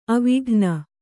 ♪ avighna